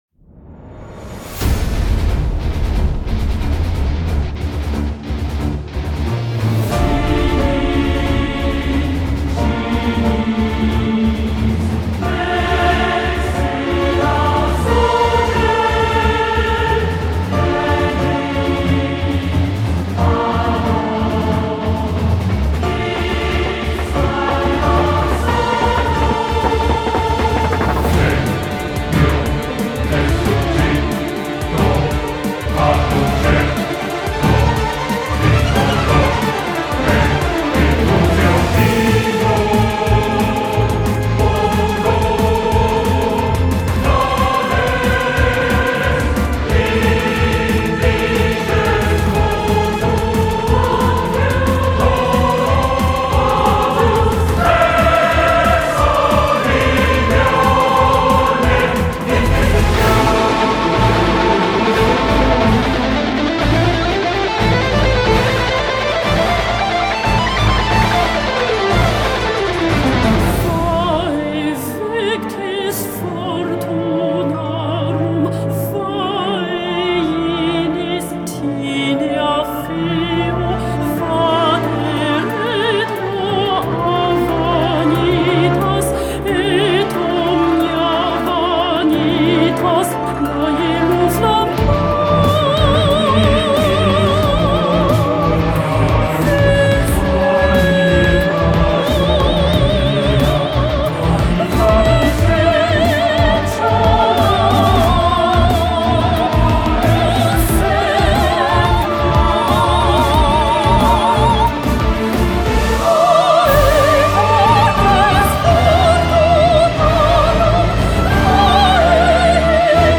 BPM181
Audio QualityCut From Video